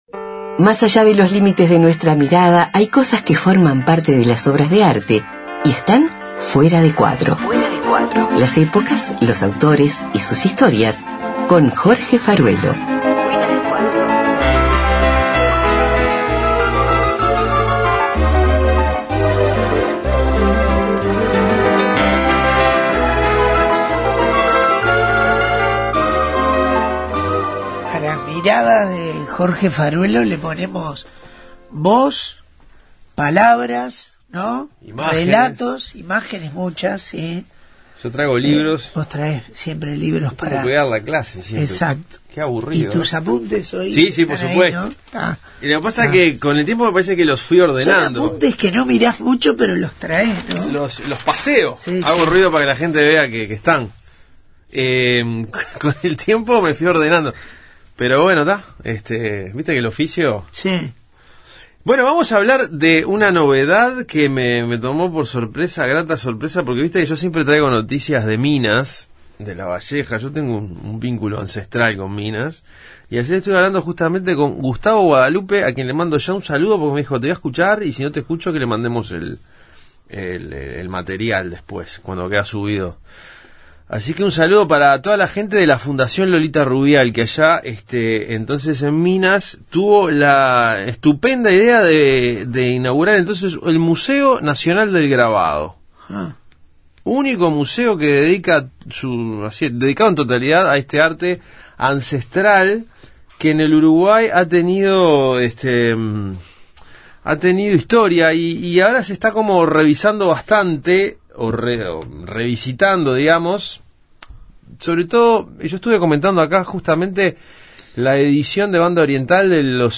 Columna de arte